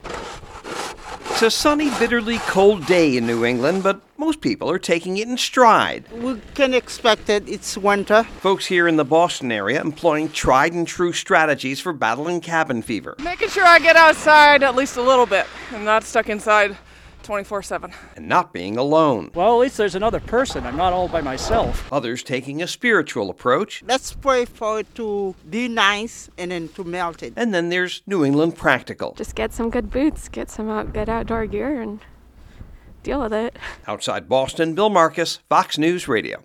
(OUTSIDE BOSTON) FEB 16 – MORE SNOW AROUND THE CORNER FOR NEW ENGLAND BUT FOR TODAY IT’S TIME TO COPE FROM THE LATEST DELUGE.